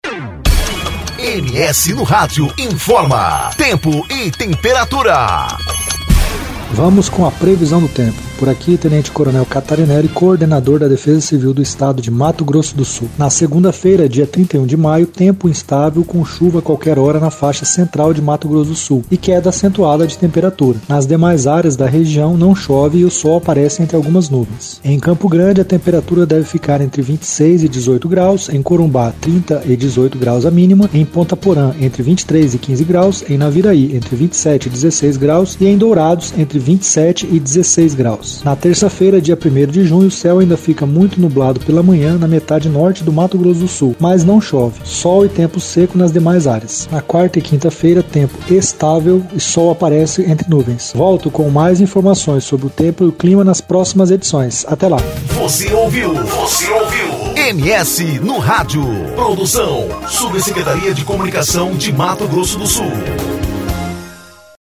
Previsão do Tempo com o coordenador estadual de Defesa Civil Tenente Coronel Fábio Catarineli, para o fim de semana.